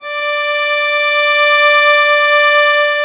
MUSETTESW.11.wav